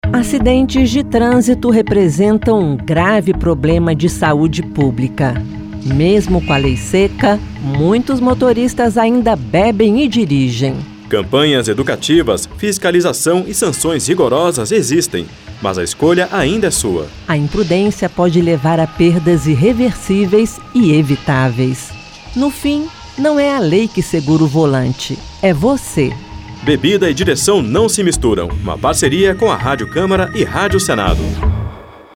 Spots e Campanhas